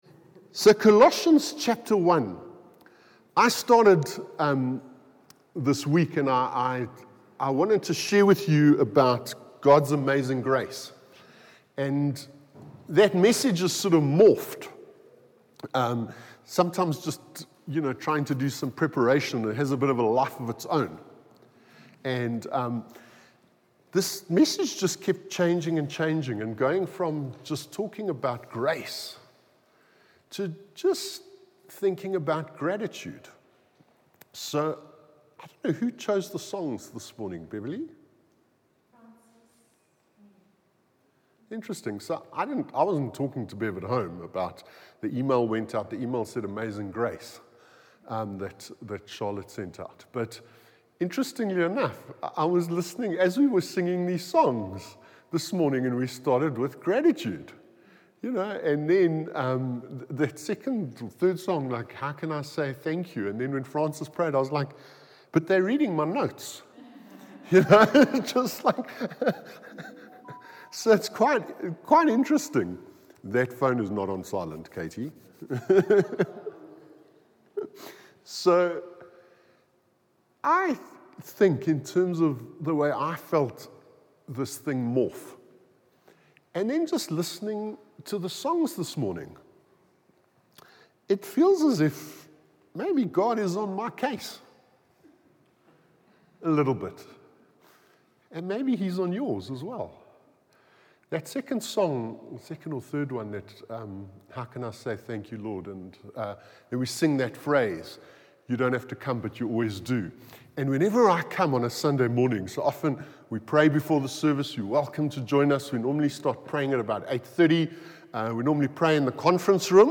From Hillside Vineyard Christian Fellowship, at Aan-Die-Berg Gemeente.